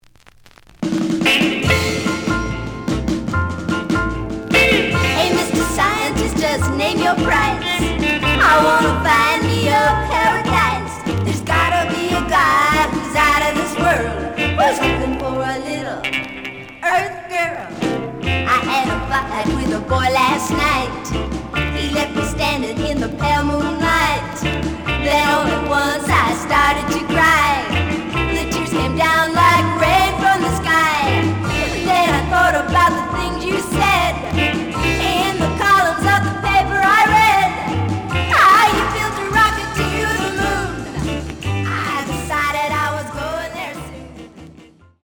The audio sample is recorded from the actual item.
●Genre: Rock / Pop
A side plays good.